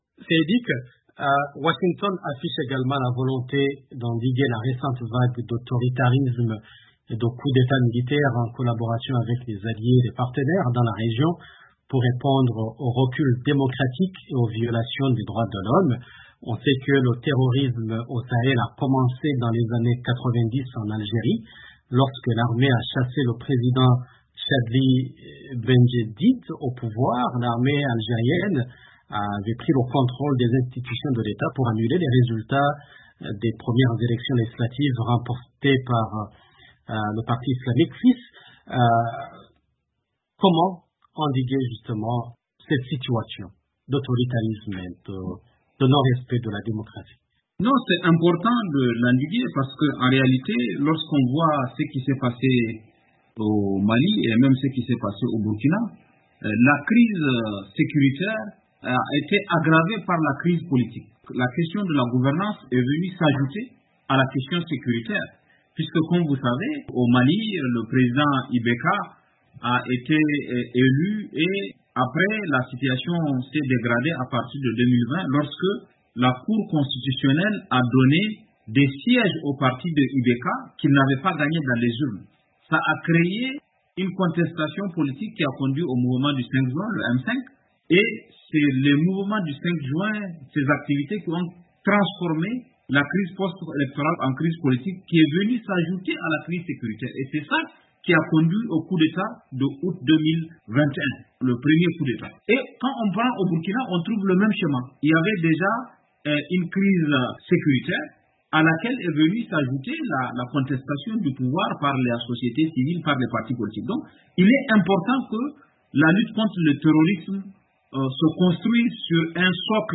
Nouvelle stratégie américaine en Afrique: débat entre experts